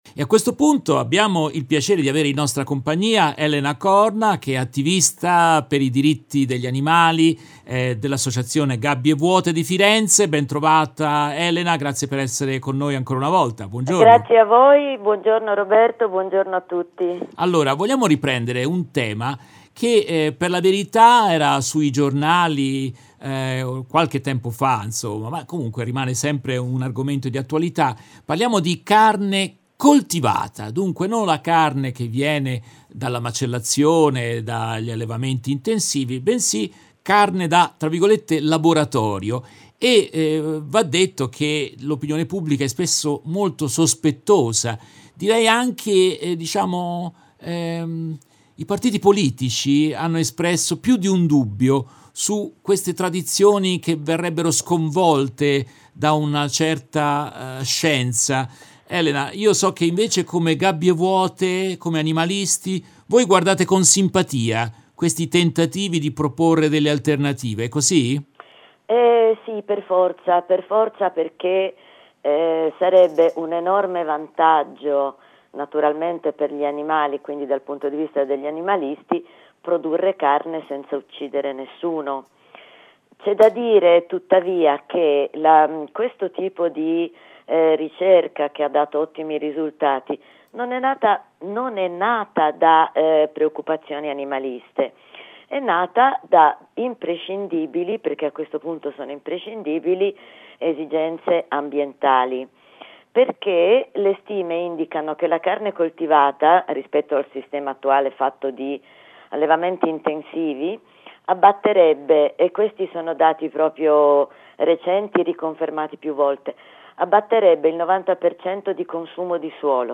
In questa nuova puntata tratta dalla diretta del 22 settembre 2023